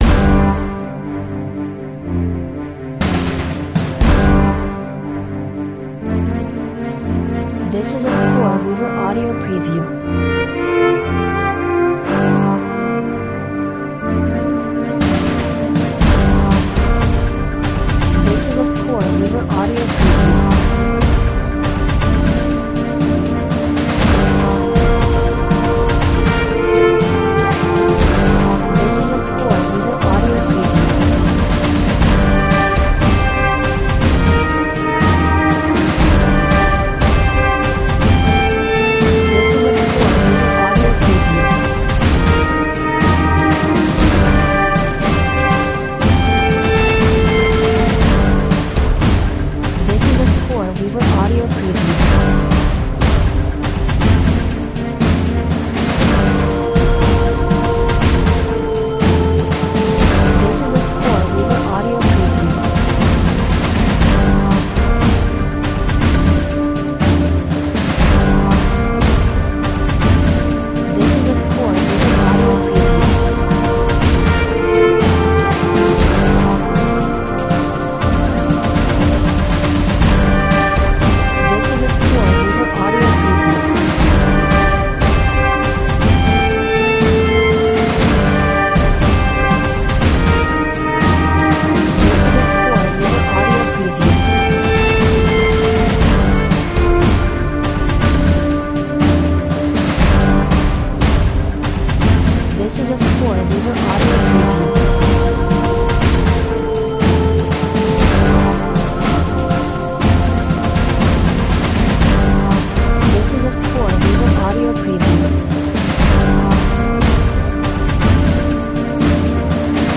Serious, determined, mighty and powerful.